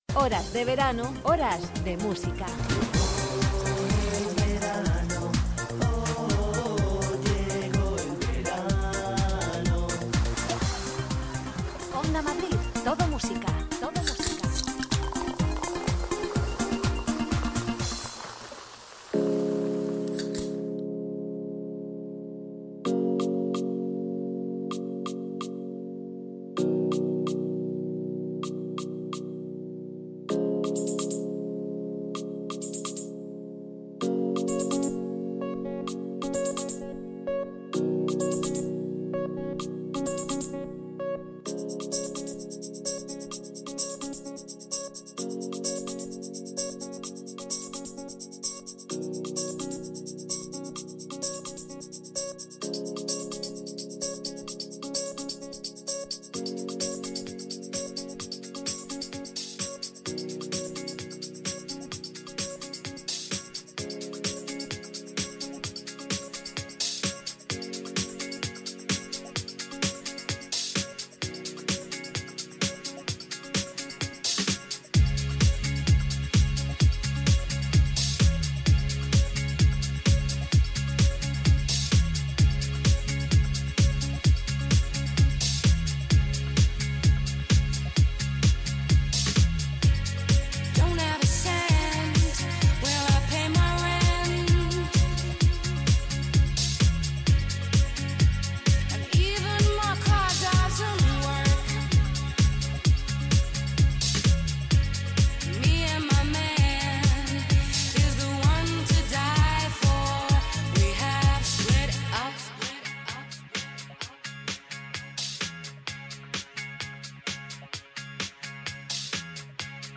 Un año más desde la radio abrimos La Terraza de Onda Madrid Todo Música para acompañarte con los éxitos que bailaste en todos tus veranos.